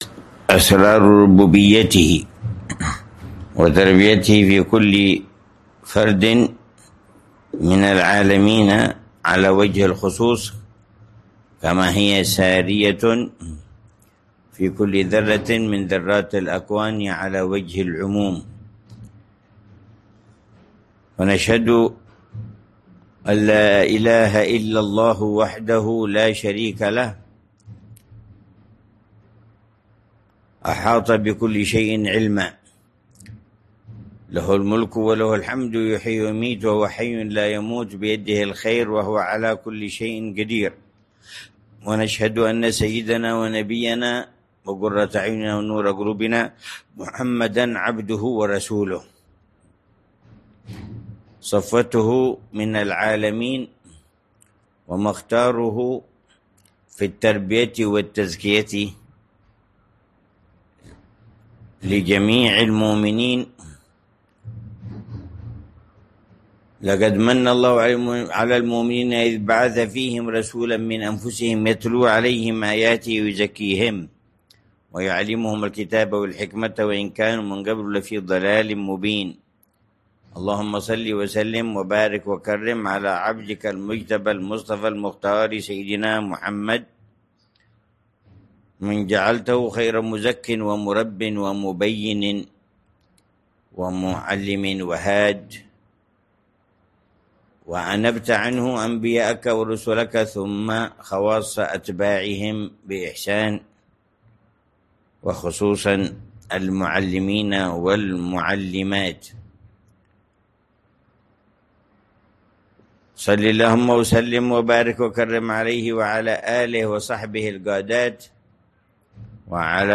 محاضرة عن التربية النبوية ومهمات المعلم والمربي على ضوئها